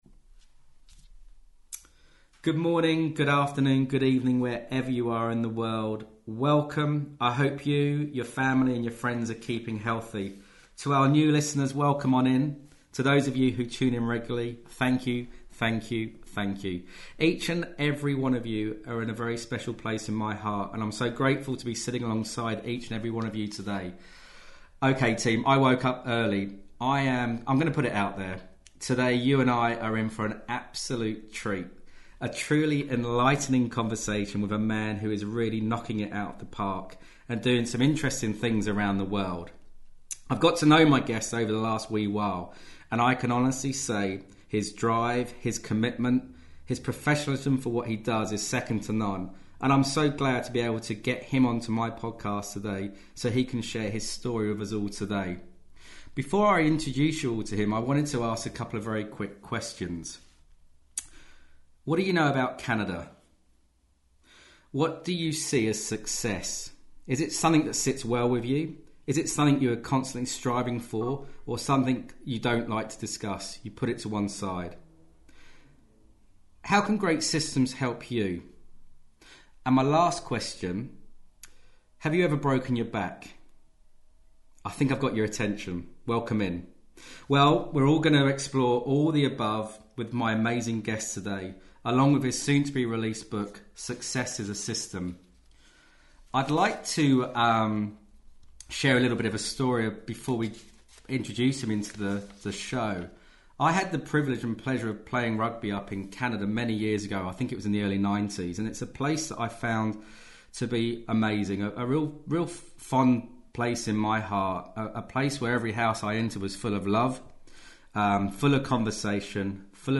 Sit back, relax and enjoy our conversation.